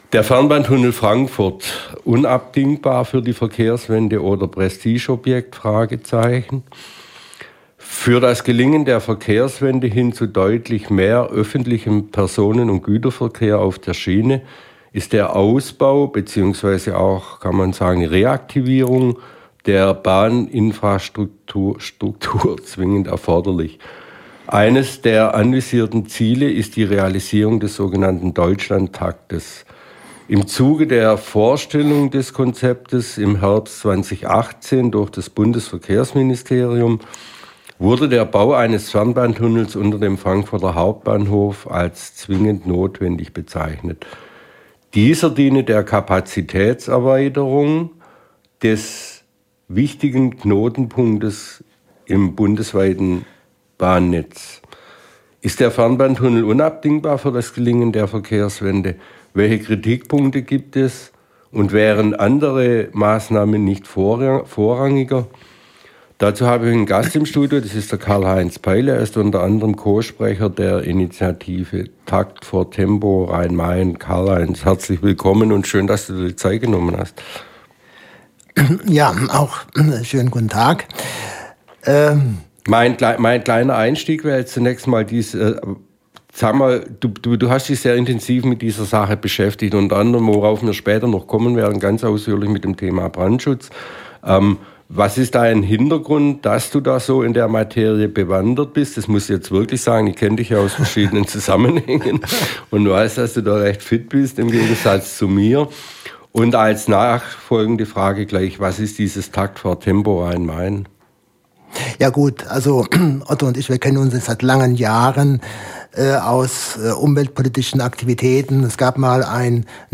Audio-Mitschnitt (ohne eingespielte Musikbeiträge – Dauer 42 min)